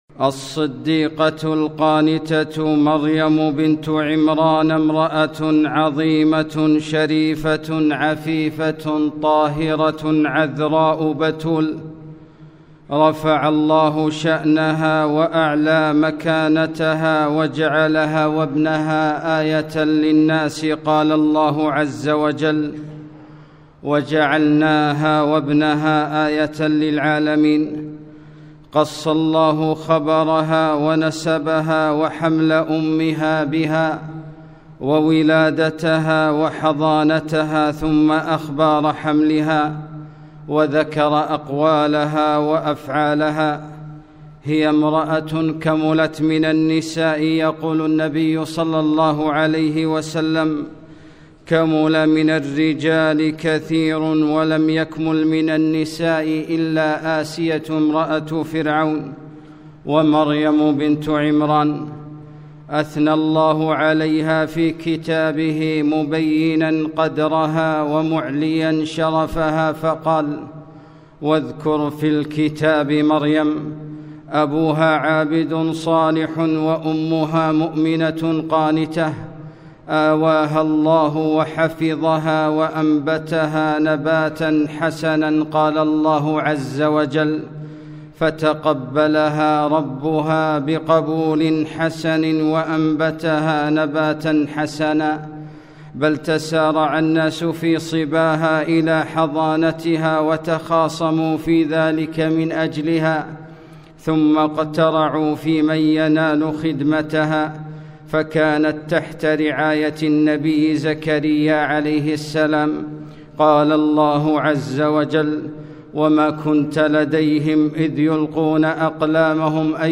خطبة - لقد جتئم شيئاَ إدًا